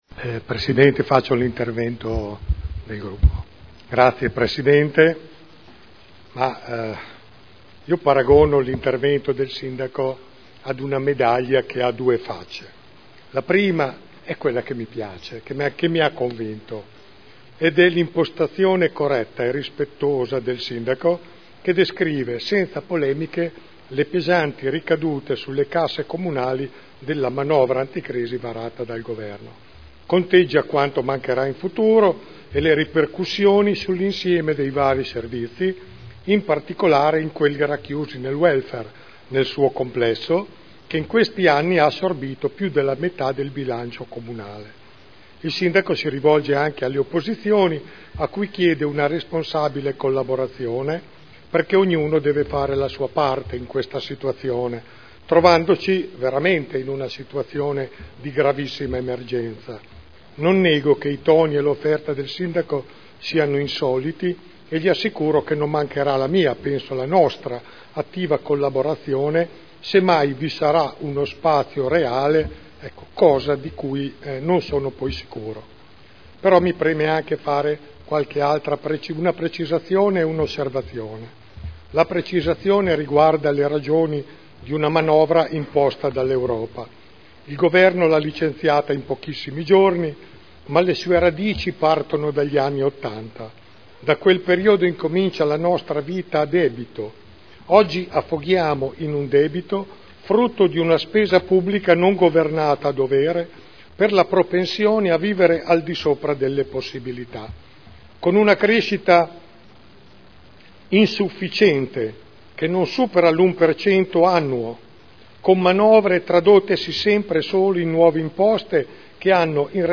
Seduta del 26 settembre 2011 Ricadute della manovra del Governo sul Bilancio del Comune di Modena – Dibattito